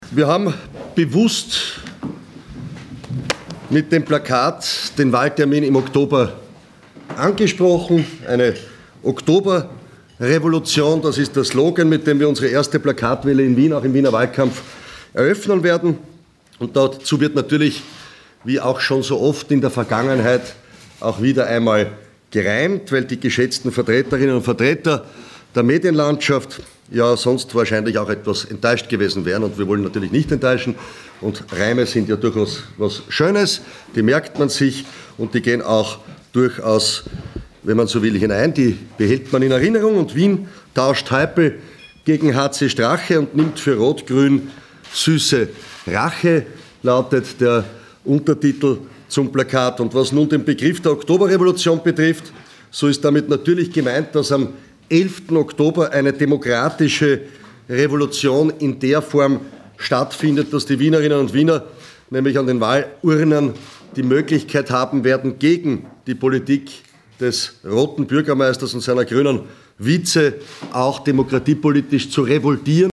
O-Töne von HC Strache